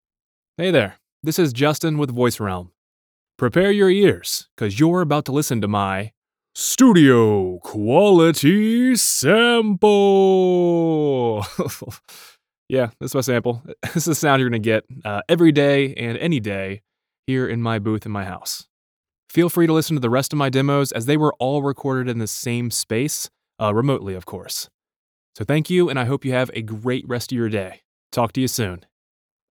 Male
Studio Quality Sample